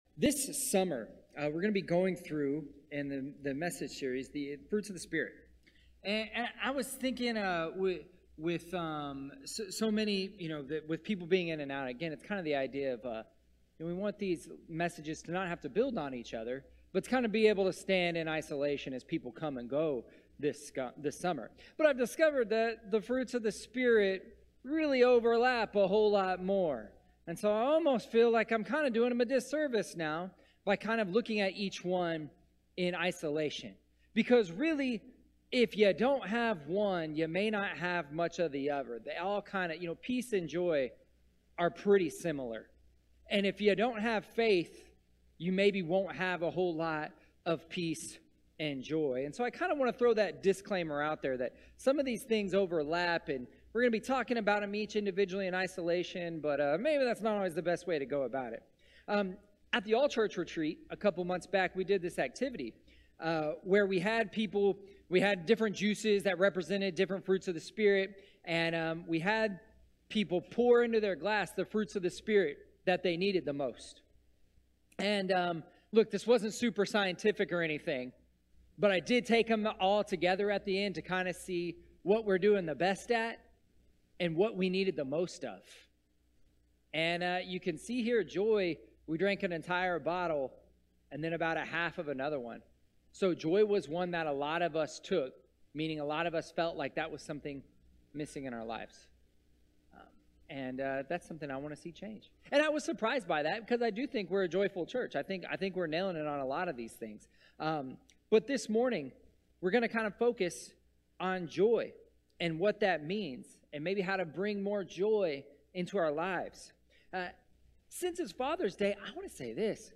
This week's bulletin - 6/18/2023 More from the series: Fruits of the Spirit series ← Back to all sermons